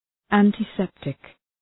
Προφορά
{,æntı’septık}